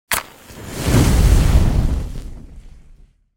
دانلود صدای آتش 12 از ساعد نیوز با لینک مستقیم و کیفیت بالا
جلوه های صوتی
برچسب: دانلود آهنگ های افکت صوتی طبیعت و محیط دانلود آلبوم صدای شعله های آتش از افکت صوتی طبیعت و محیط